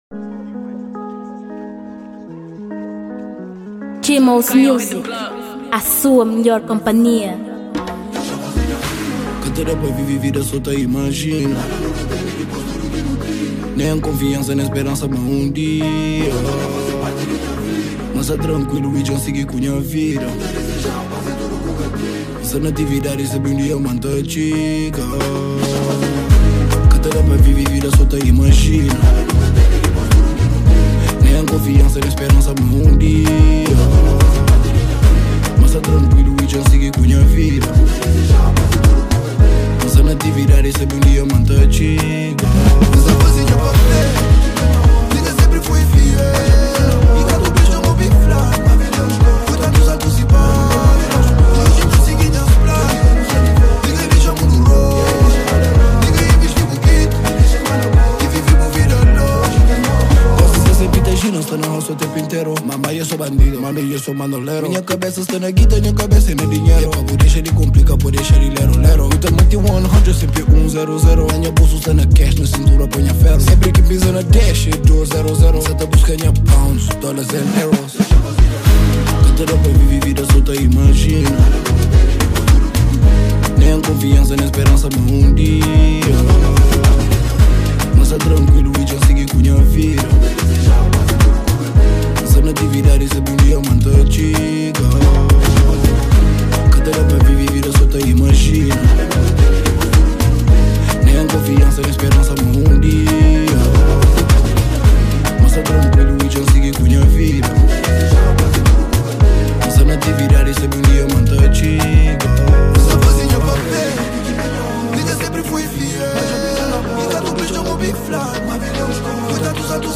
2023 Gênero: Afro Beat Tamanho